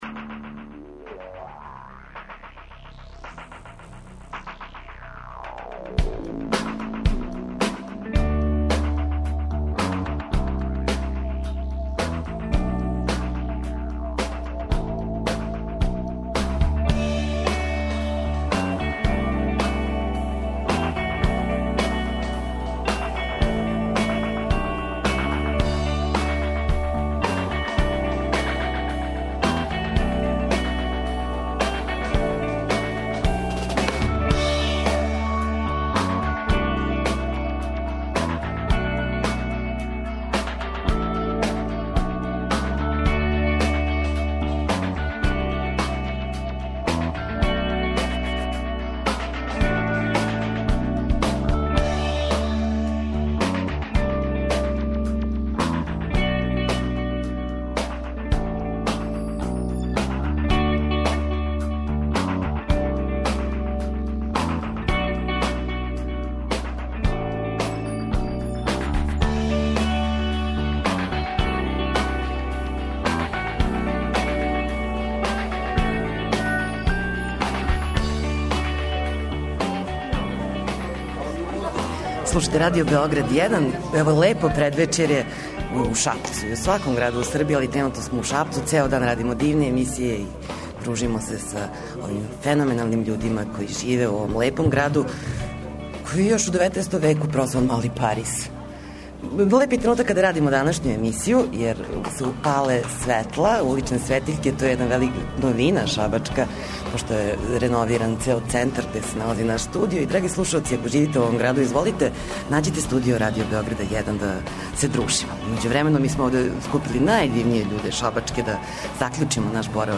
Ритам попа овога пута стиже на таласима Београда 1 из Шапца. Причаћемо о Шабачком летњем фестивалу, најавићемо Блуз и Џез Фестивал који почиње следеће недеље у том граду.